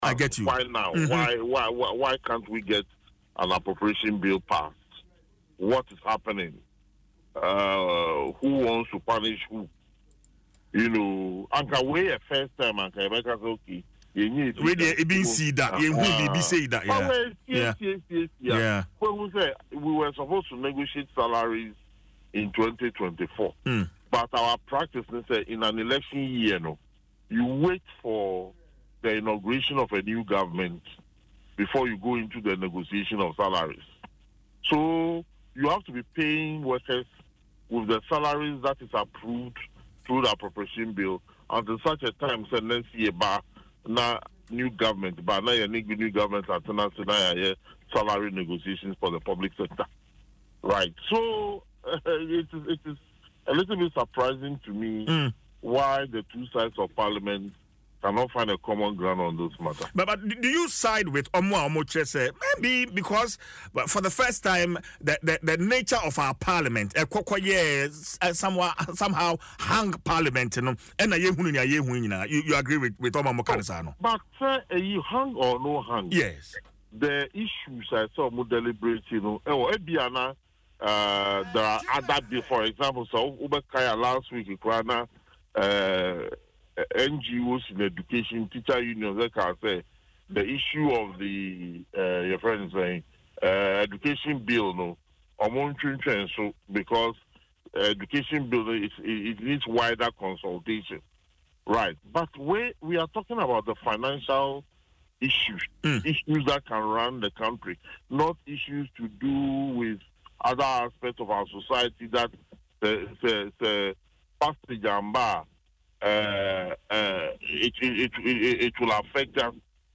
He said this in an interview on Adom FM’s morning show, Dwaso Nsem, Thursday.